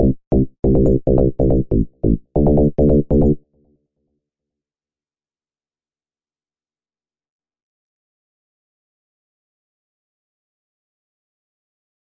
一个由我创造的贝斯。140 BPM